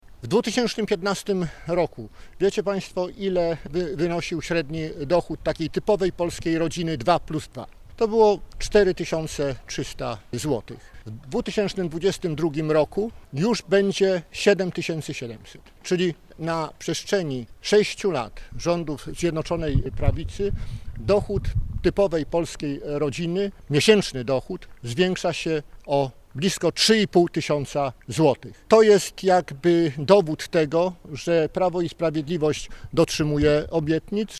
Pięć głównych filarów tworzących nowy „Polski Ład” prezentowali dziś na zielonogórskim deptaku politycy Prawa i Sprawiedliwości, którzy zapowiedzieli, że po wdrożeniu programów społecznych, czas na wzmocnienie klasy średniej.
Nowe pomysły gospodarcze mają sprawić, że Polacy staną się bardziej zamożni. Marek Ast, prezes lubuskich struktur Prawa i Sprawiedliwości przypomniał, że od 2015 roku, dochód polskich rodzin zwiększył się średnio z 4300 zł do ponad 7500 zł: